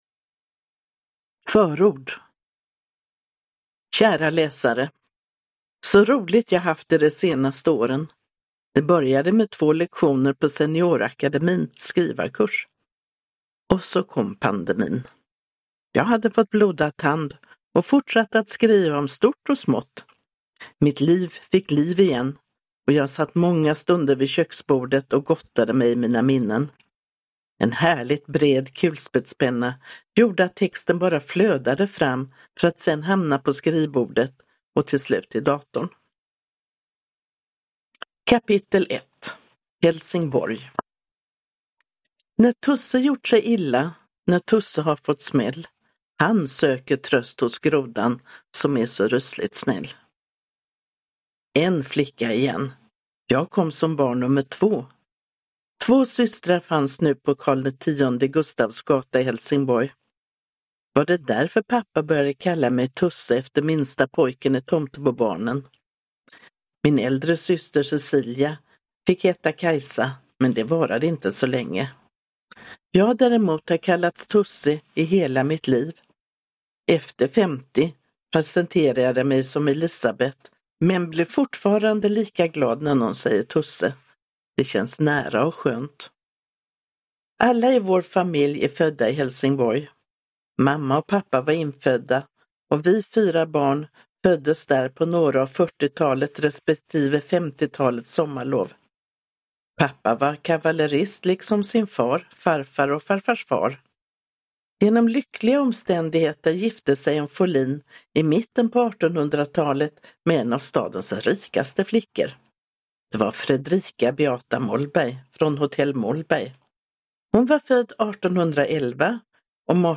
Ett liv, mitt liv – Ljudbok